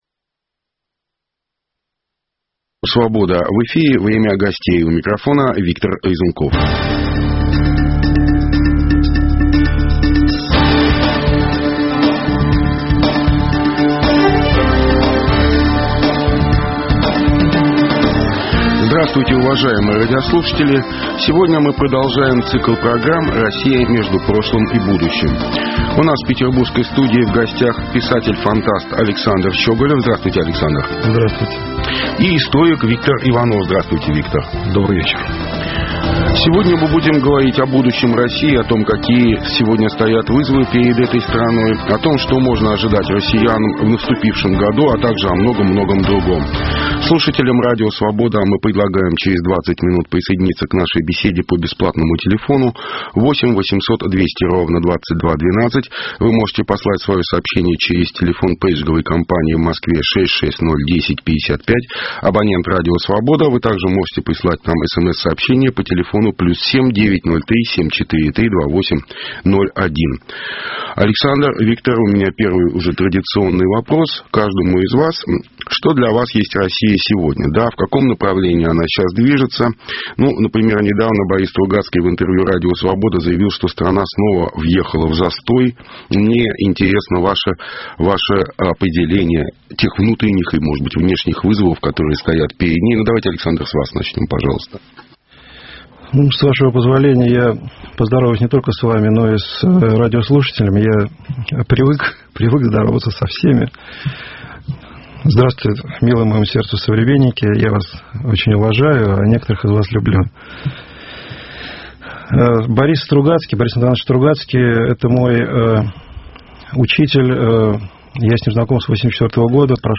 беседуем с историком